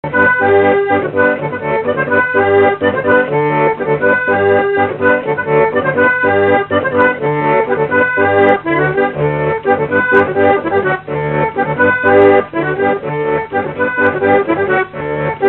Note maraîchine
Résumé instrumental
danse : branle : courante, maraîchine
Répertoire à l'accordéon diatonique